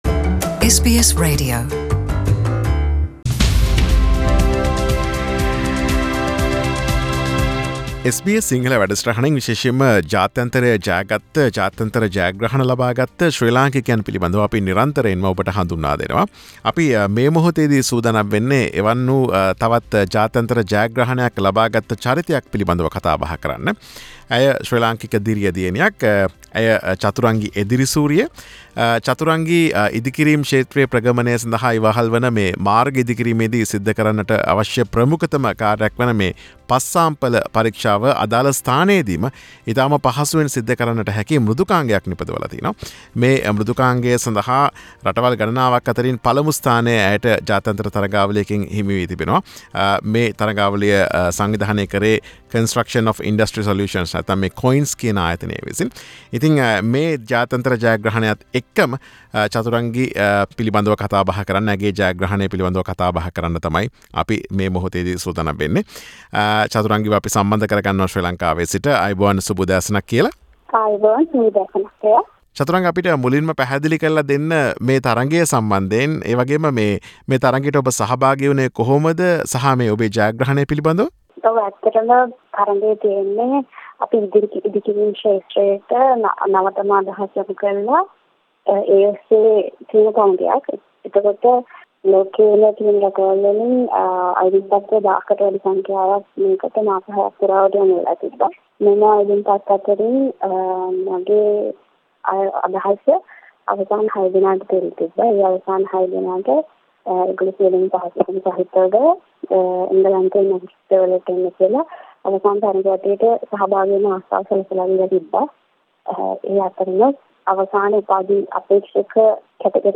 සිදුකළ සාකච්ඡාව.